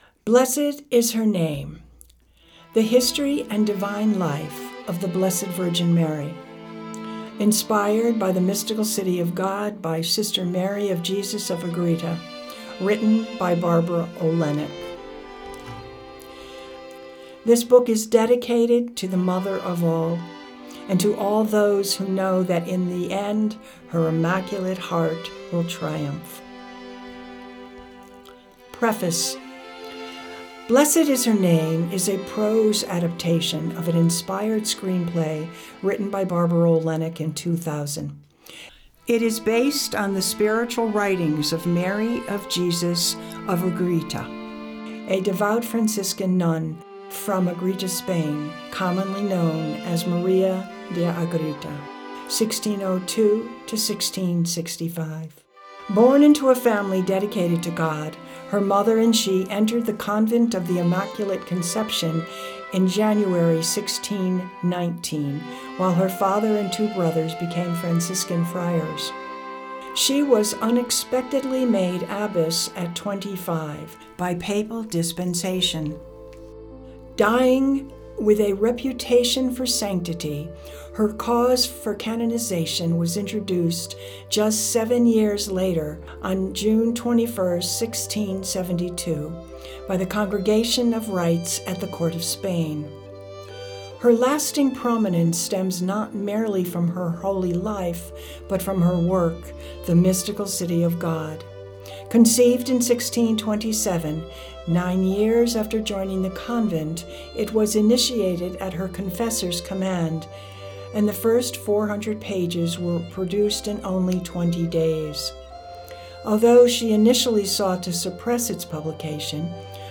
from the Blessed Is Her Name - Audiobook